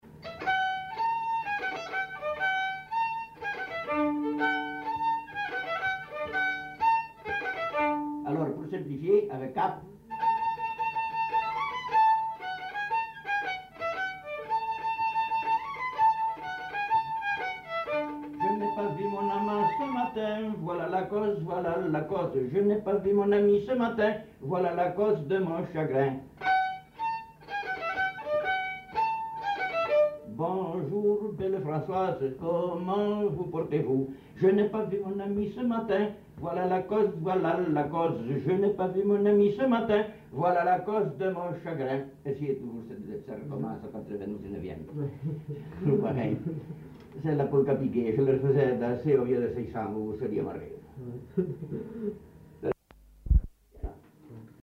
Lieu : Orbessan
Genre : chanson-musique
Type de voix : voix d'homme
Production du son : chanté
Instrument de musique : violon
Danse : polka piquée